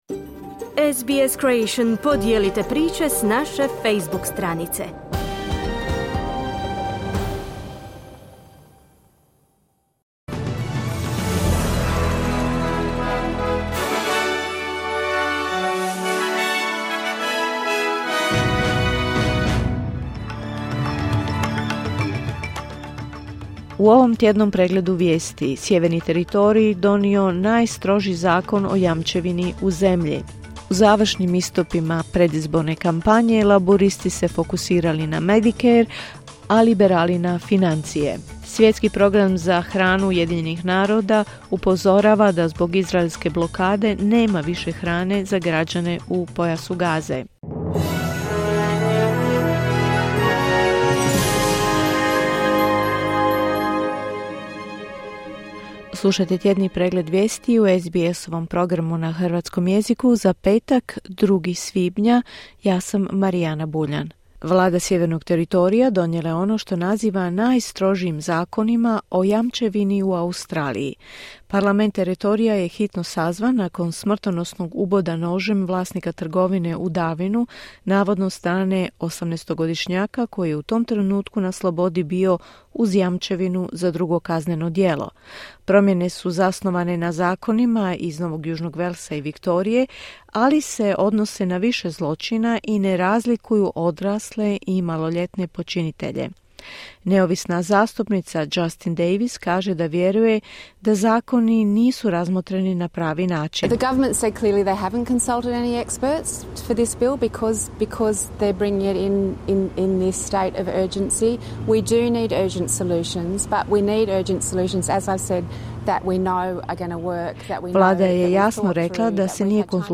Vijesti radija SBS.